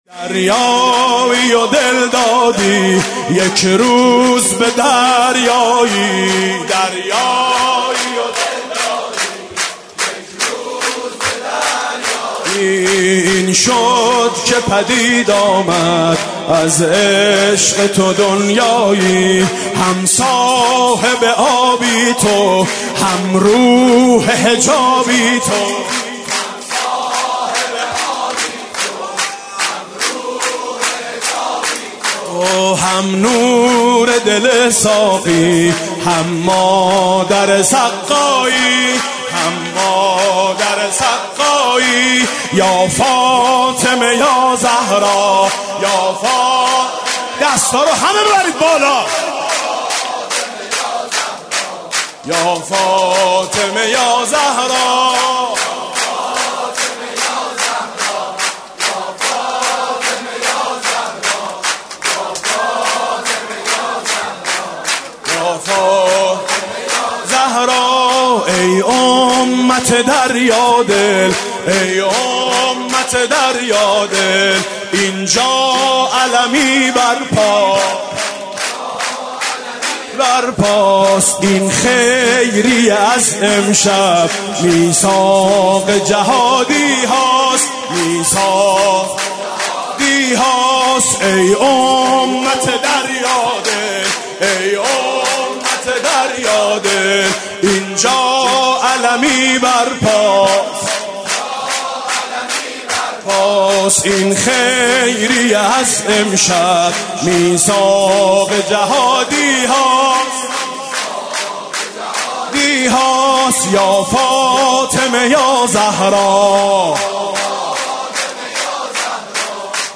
سرود: ای امت دریا دل، این جا علمی بر پاست